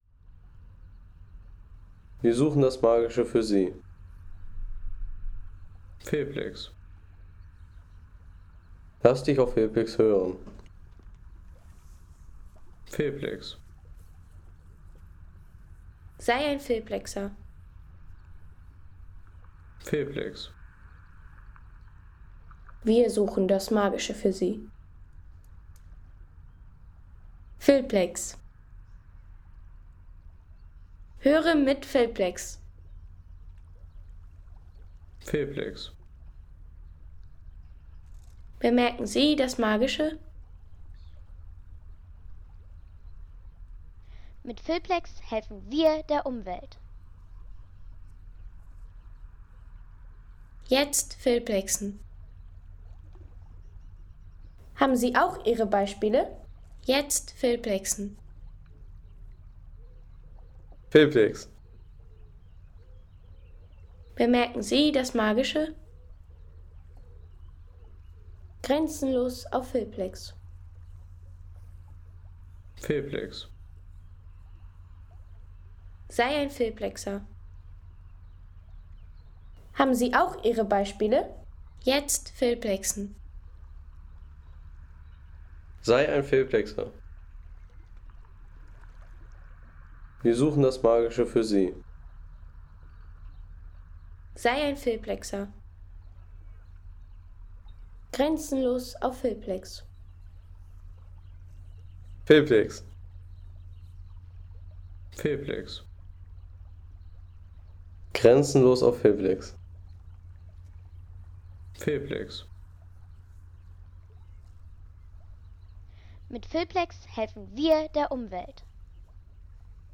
Pause an der Elbe - Jork